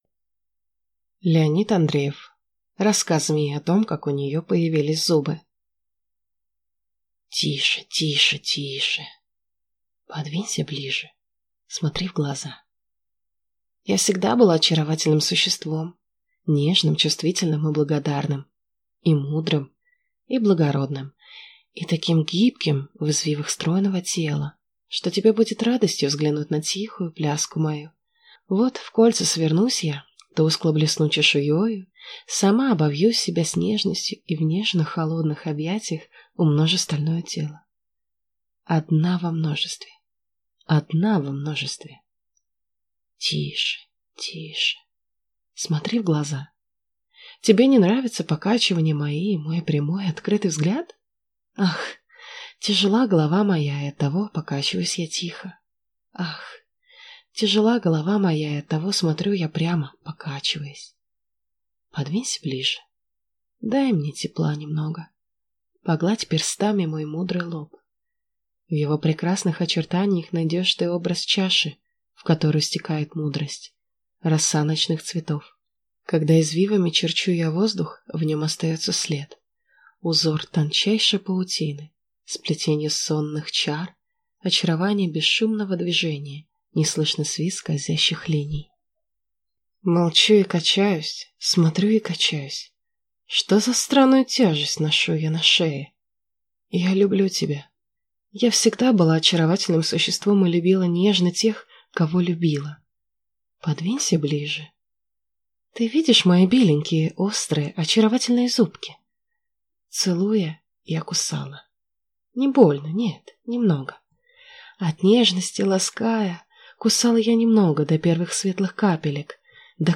Аудиокнига Рассказ змеи о том, как у нее появились зубы | Библиотека аудиокниг